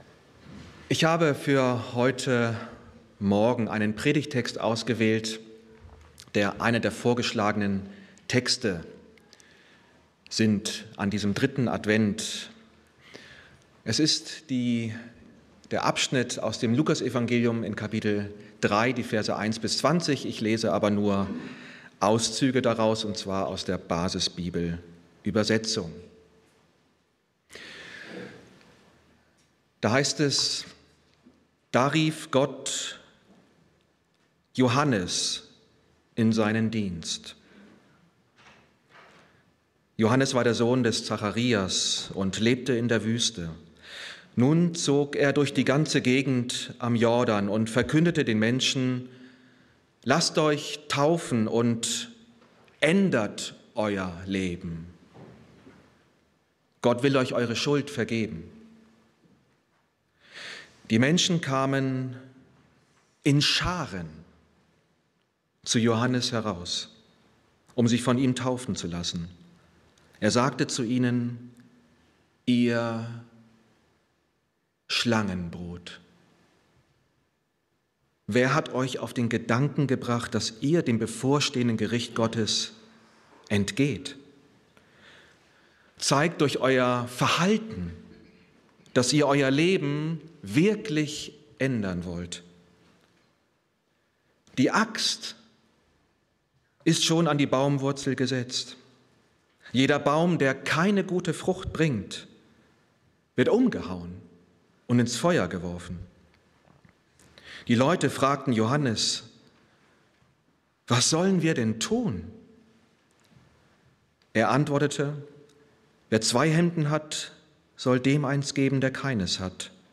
Predigten aus der Gemeinde in Bietigheim